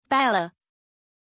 Pronuncia di Bella
pronunciation-bella-zh.mp3